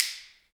Index of /90_sSampleCDs/Roland L-CDX-01/PRC_Clap & Snap/PRC_Snaps